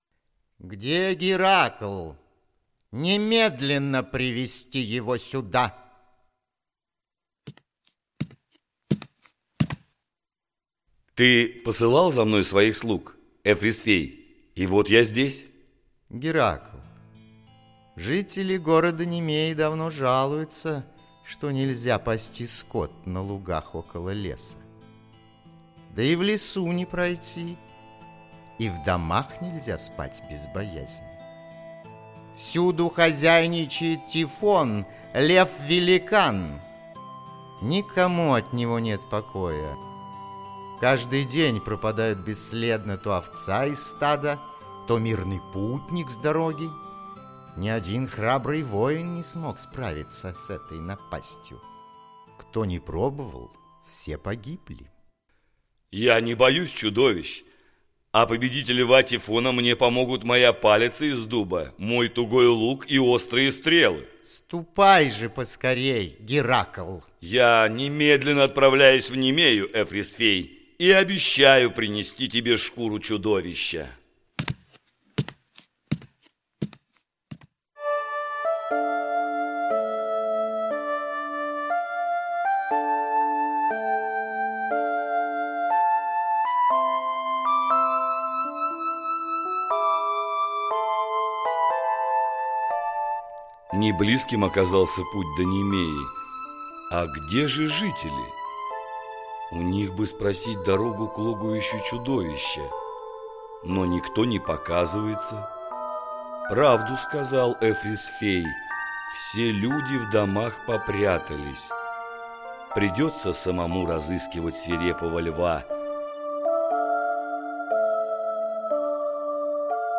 Аудиокнига Подвиги Геракла, часть 1 и 2 | Библиотека аудиокниг